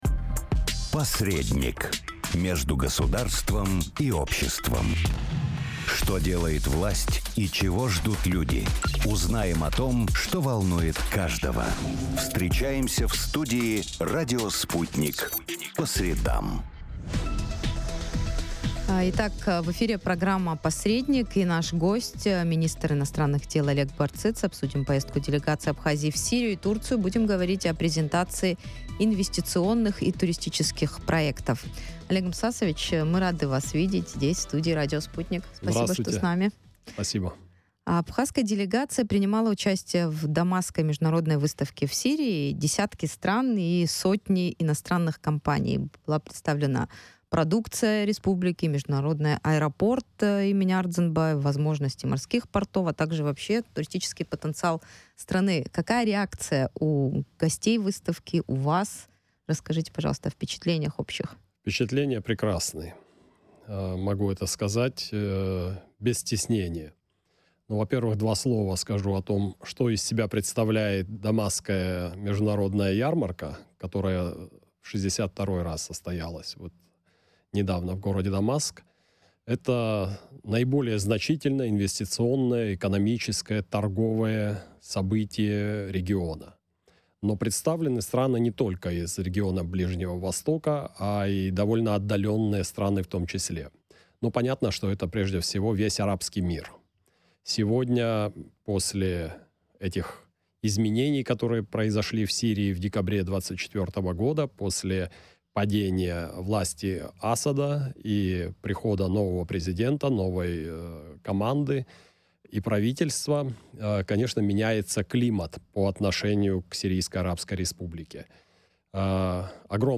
Визиты в Сирию и Турцию. Интервью с министром иностранных дел Абхазии